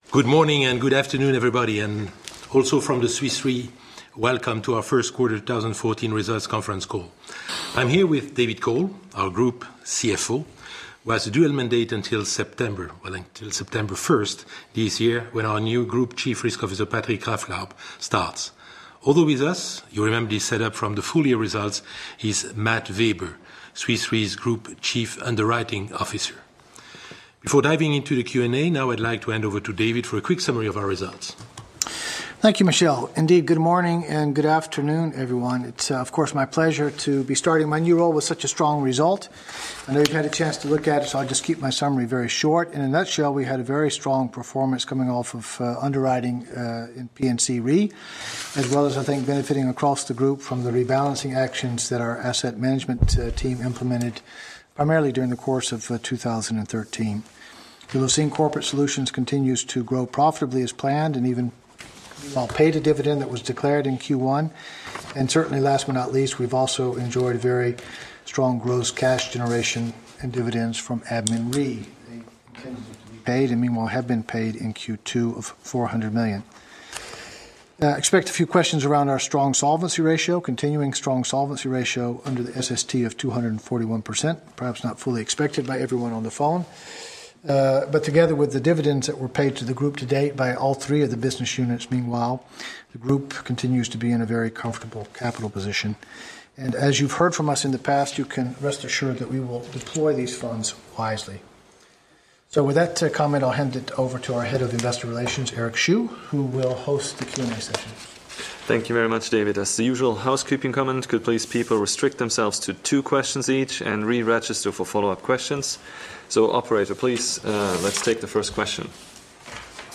Analysts Conference call recording
Q1_2014_Analysts_Call_hi.mp3